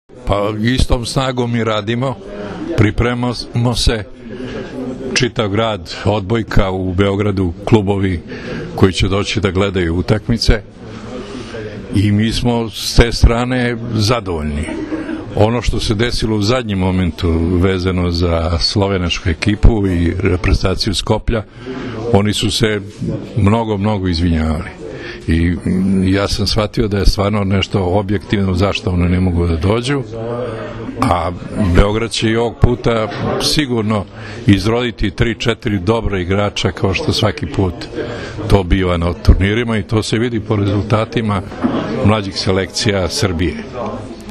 Povodom „Trofeja Beograd 2014.“ – 49. Međunarodnog turnira Gradskih omladinskih reprezentacija, koji će se odigrati od 1. – 3. maja, danas je u sali Gradske uprave Grada Beograda održana konferencija za novinare.